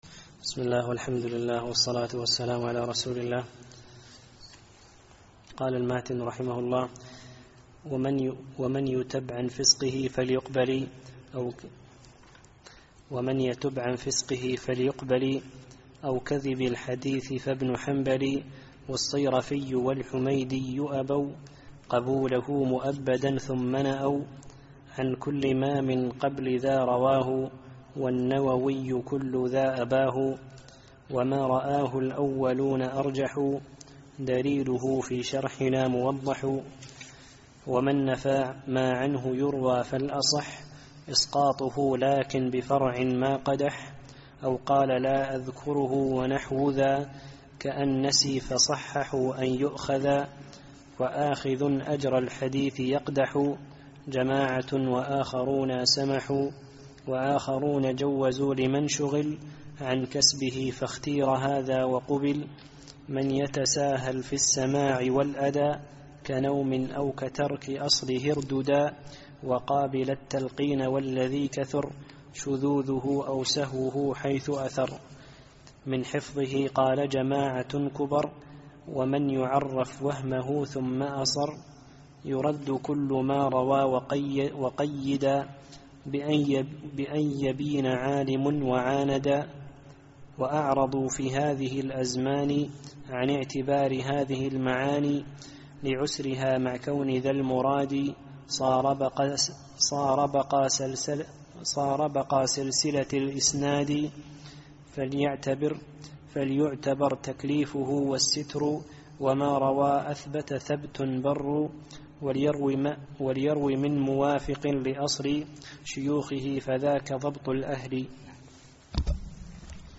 الدرس السادس عشر